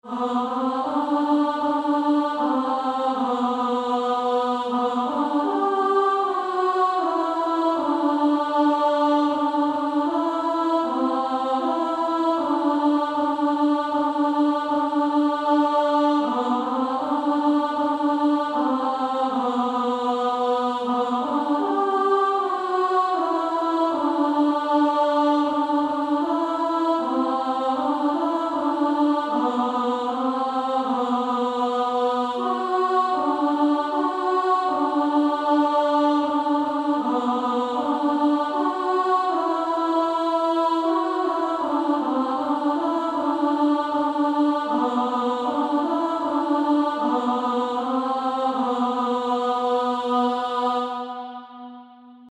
Alto Track.
Practice then with the Chord quietly in the background.